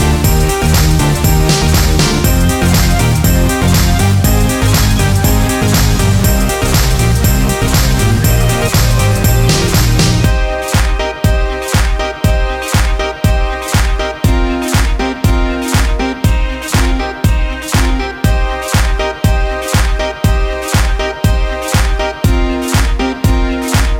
no Backing Vocals Dance 3:28 Buy £1.50